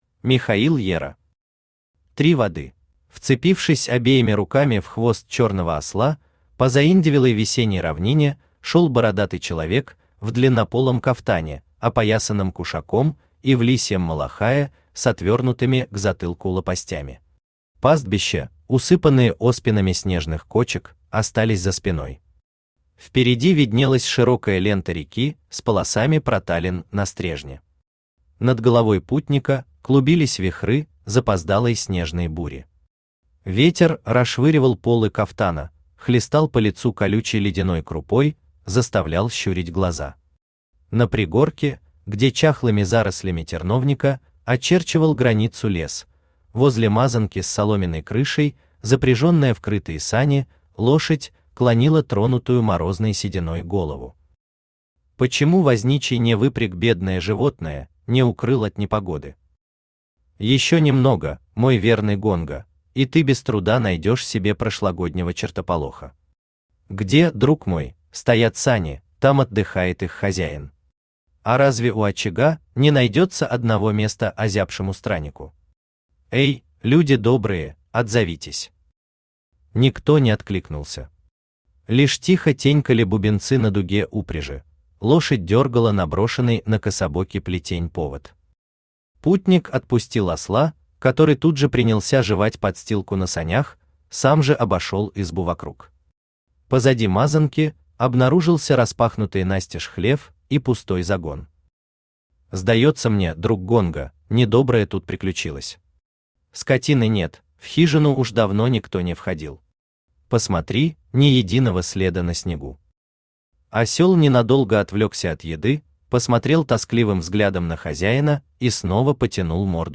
Aудиокнига Три воды Автор Михаил Ера Читает аудиокнигу Авточтец ЛитРес.